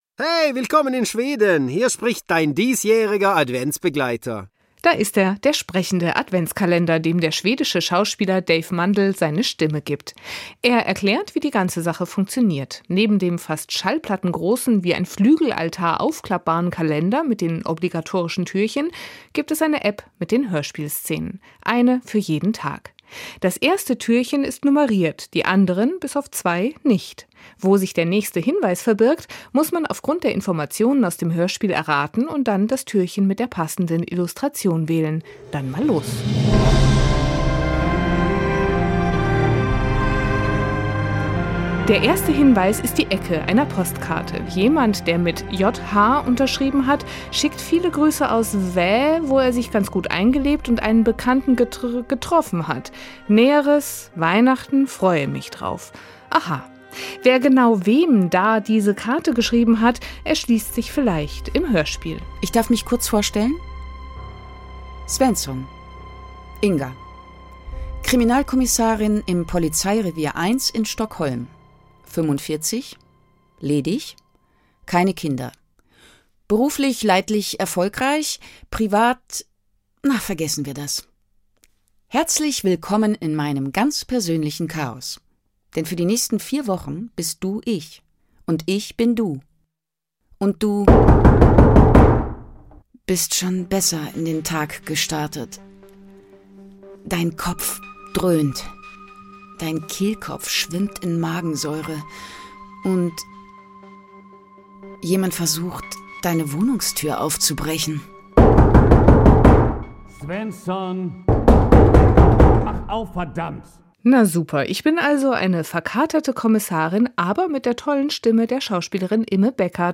hoerbuch-stiller-als-die-nacht-kai-karlsson.mp3